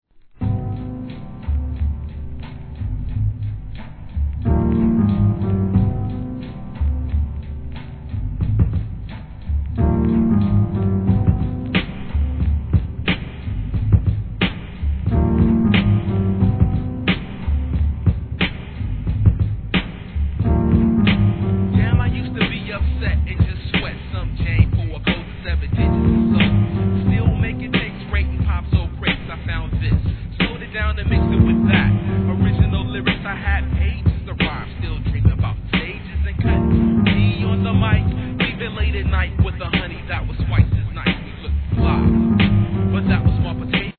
1. HIP HOP/R&B
多くのMIXでもお馴染みのJAZZY HIP HOP!!